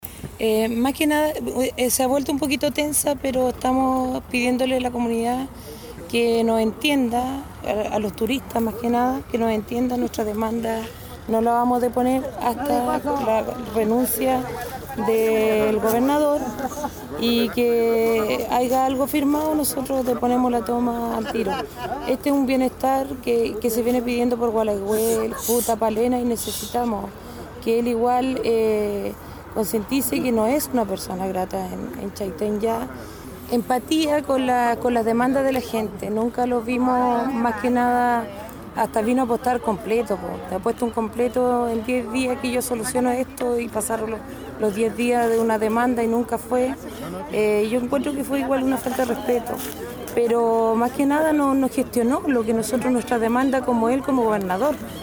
Esto, luego que sesionara en la ciudad el Consejo regional, y hasta donde llagó el grupo de habitantes para dar a conocer sus problemáticas de conectividad, educacionales y servicios básicos, entre otros como lo relató la concejala Delma Ojeda.